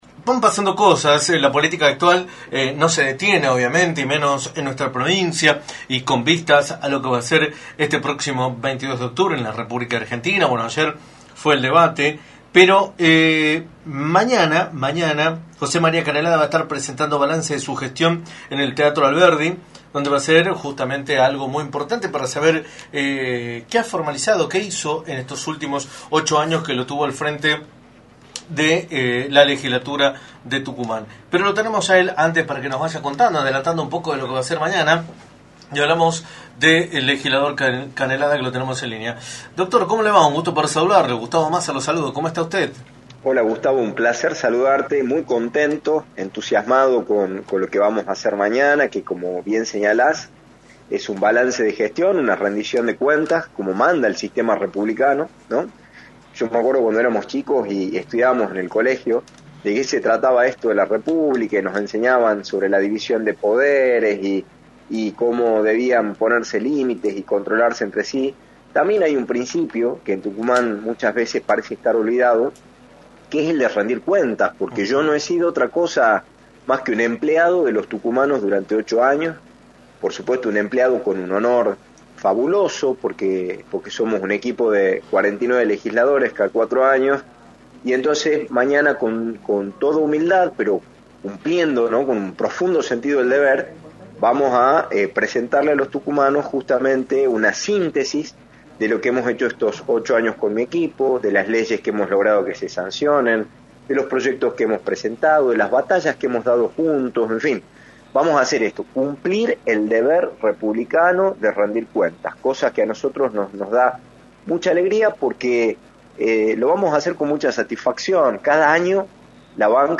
José Canelada, Legislador y Concejal electo de San Miguel de Tucumán, indicó en Radio del Plata Tucumán, por la 93.9, que el martes presentará en el Teatro Alberdi un balance de sus 8 años como Legislador.